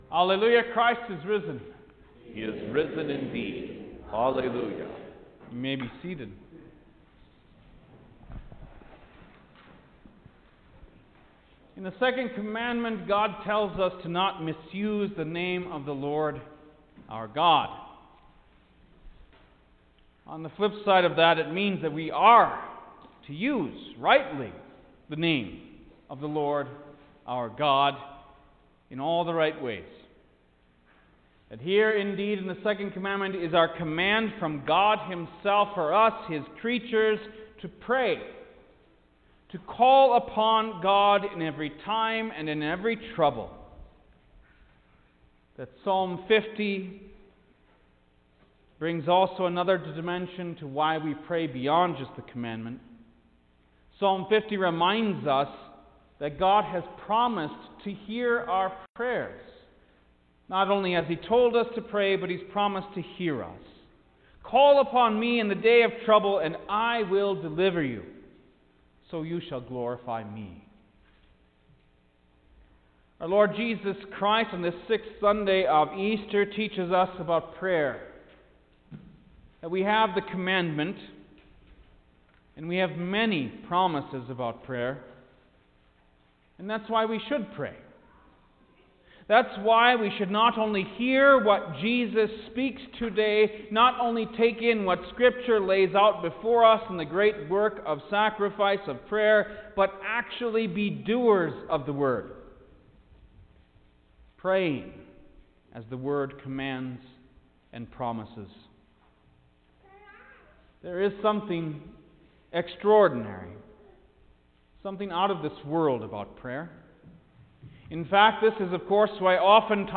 May-9_2021-Sixth-Sunday-of-Easter_Sermon-Stereo.mp3